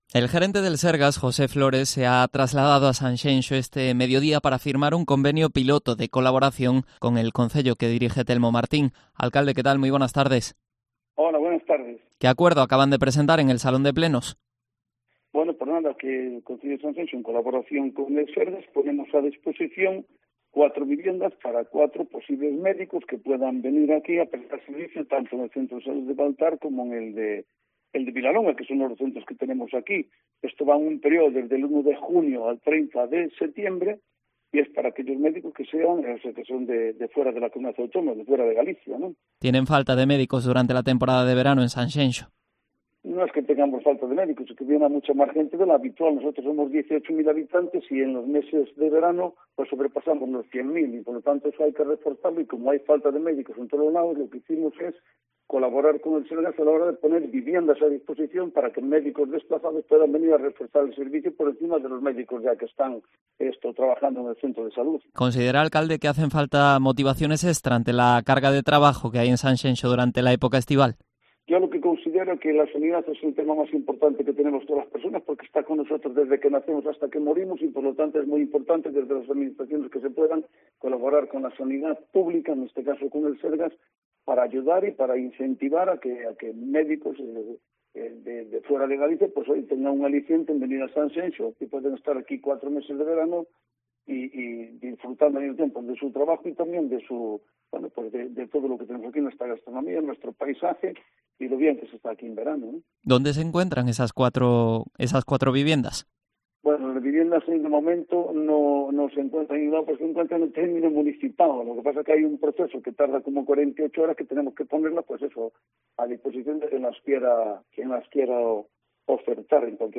Entrevista a Telmo Martín, alcalde de Sanxenxo